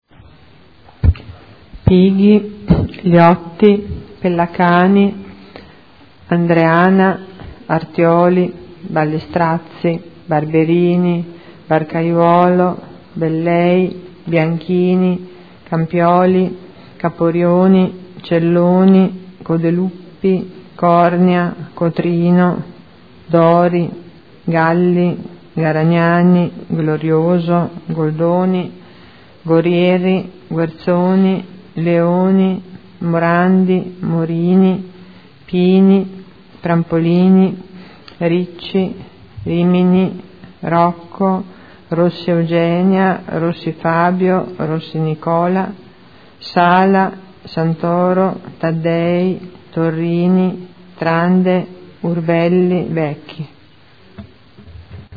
Seduta del 05/09/2011. Appello